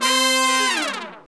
Index of /90_sSampleCDs/Roland L-CDX-03 Disk 2/BRS_R&R Horns/BRS_R&R Falls